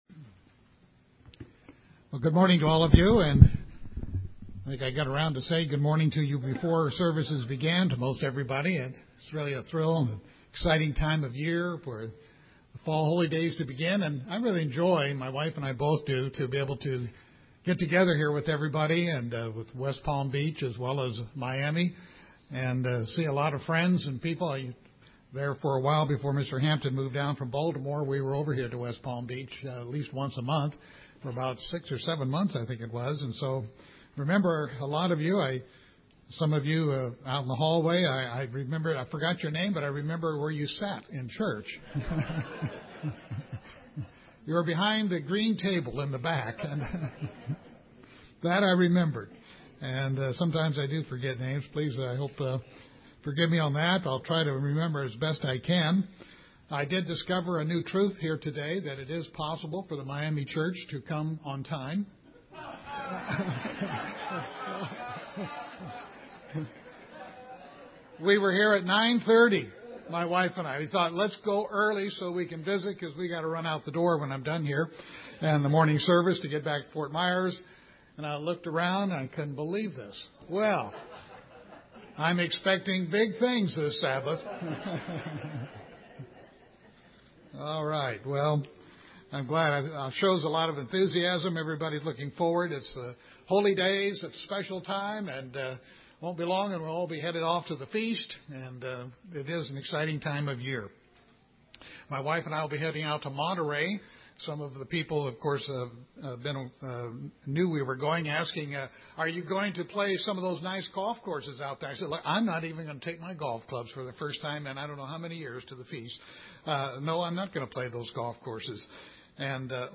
9-29-11 A.M. Sermon.mp3